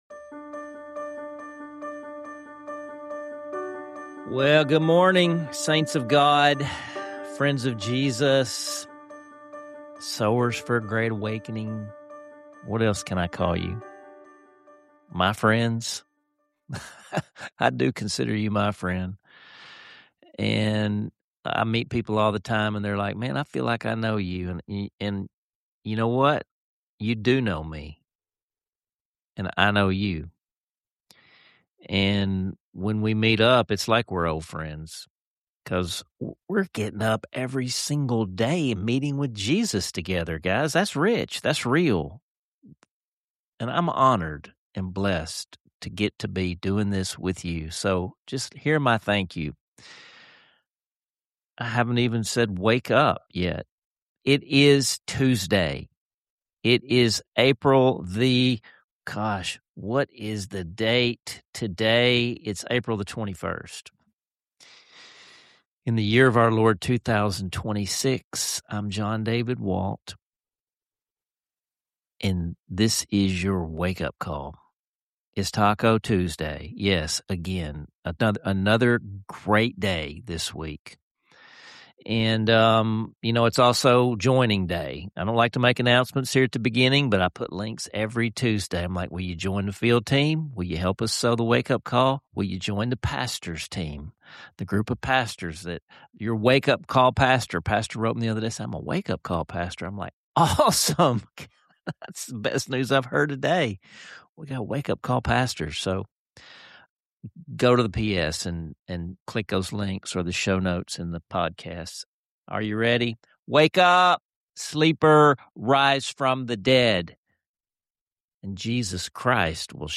Step inside the historic Seed House in Gillette, Arkansas, where each day begins with sowing seeds—both literal and spiritual—for a great awakening.